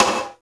Index of /90_sSampleCDs/Roland L-CD701/SNR_Rim & Stick/SNR_Rim Modules
SNR CLOG SN4.wav